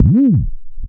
BassRoll.wav